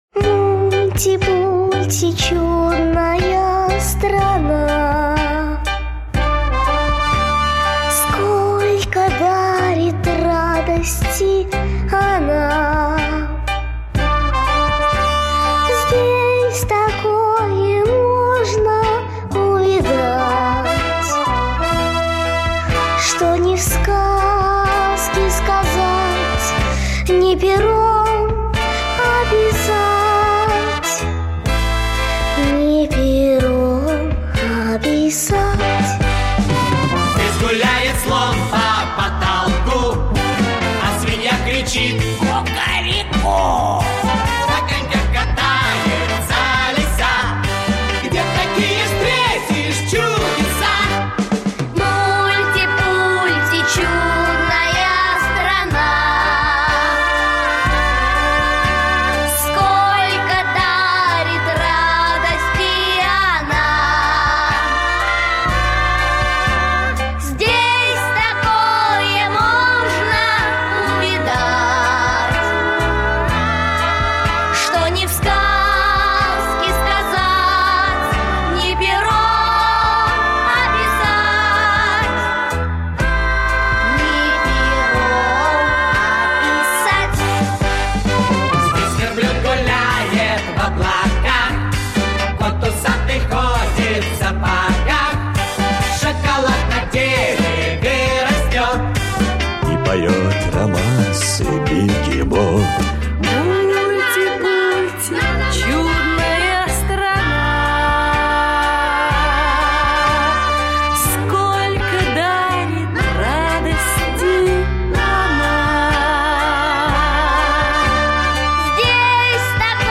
Слушайте Путешествие в стране Мульти-Пульти - аудиосказка-мультфильм. Сказка-путешествие со старухой Шапокляк по разным мультфильмам.